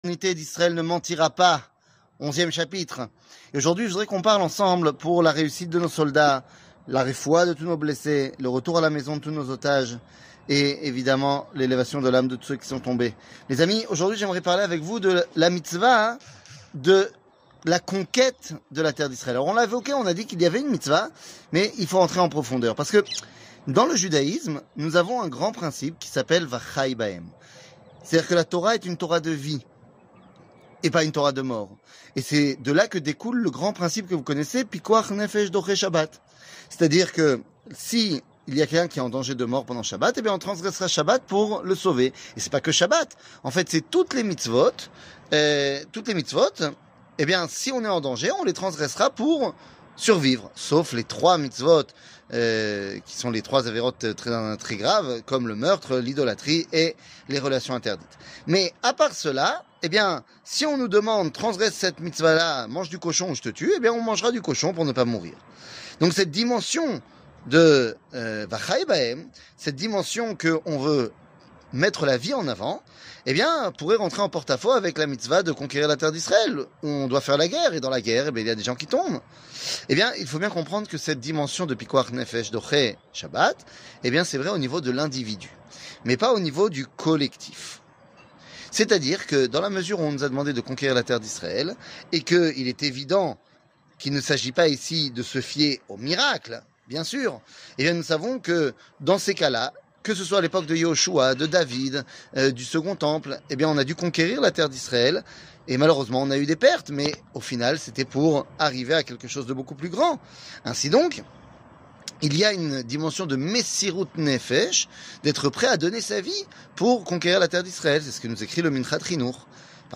L'éternité d'Israel ne mentira pas ! 11 00:05:07 L'éternité d'Israel ne mentira pas ! 11 שיעור מ 22 אוקטובר 2023 05MIN הורדה בקובץ אודיו MP3 (4.67 Mo) הורדה בקובץ וידאו MP4 (8.46 Mo) TAGS : שיעורים קצרים